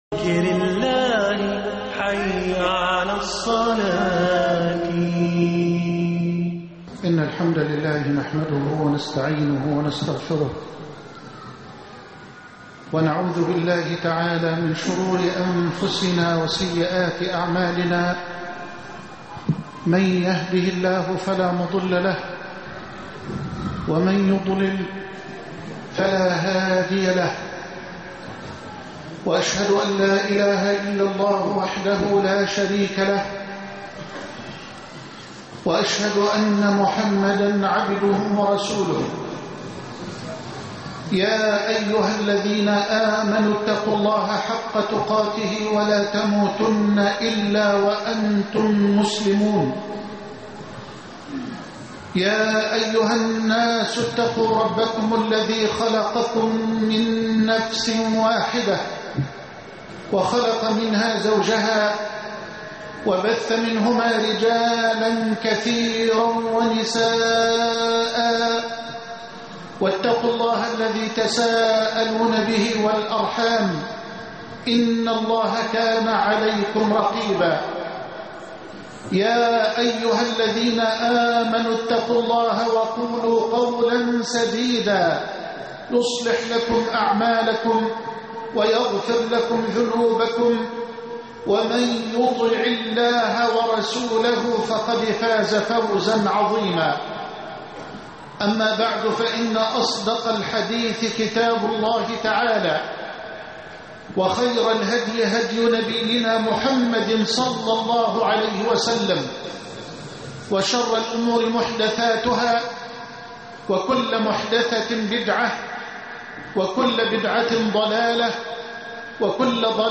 ثلاث كلمات نبوية لحل الازمة المصرية(3-2-12) خطب الجمعة - فضيلة الشيخ محمد حسان